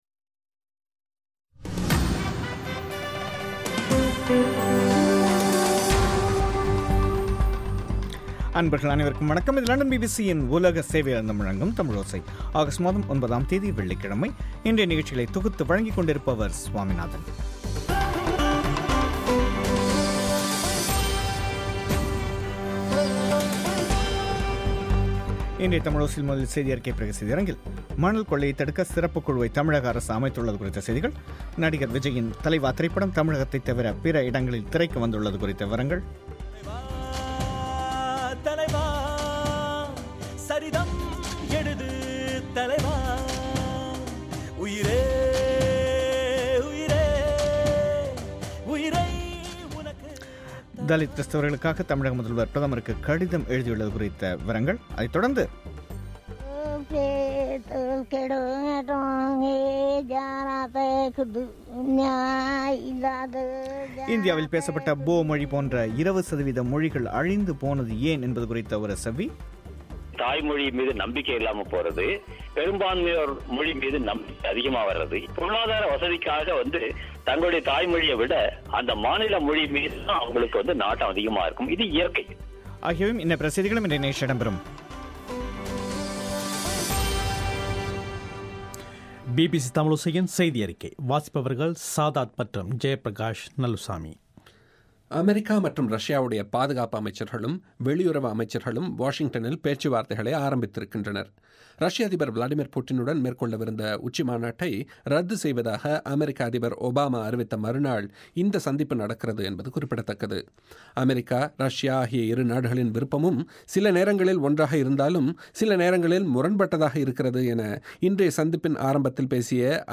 இந்தியாவில் பேசப்பட்ட போ மொழி போன்ற 20 சதவீத மொழிகள் அழிந்து போனது ஏன் என்பது குறித்த ஒரு பேட்டியும், யாழ்பாணத்தில் கரை ஒதுங்கிய அடையாளம் காணப்படாத சடலங்களைப் புதைக்க நடவடிக்கை எடுக்கப்படுவது குறித்தும் கேட்கலாம்.